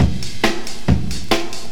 • 139 Bpm Drum Groove G Key.wav
Free drum groove - kick tuned to the G note. Loudest frequency: 712Hz
139-bpm-drum-groove-g-key-MEl.wav